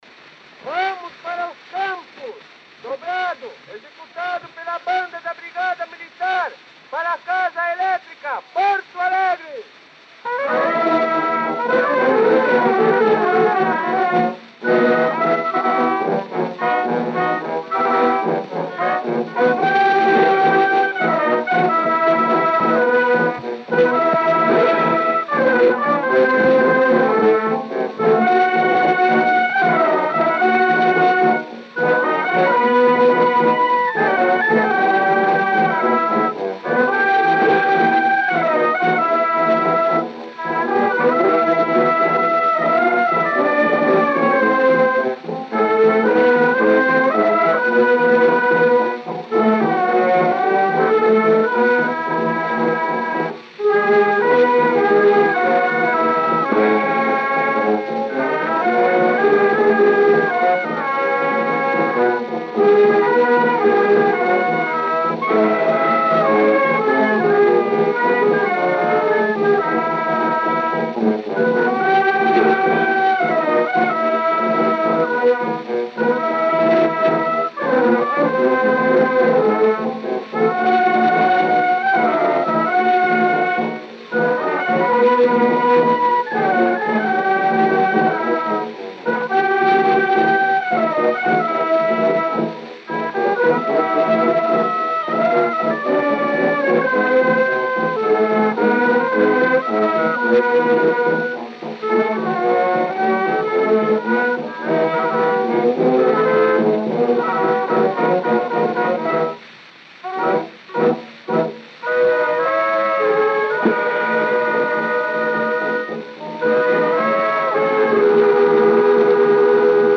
Gênero: Dobrado.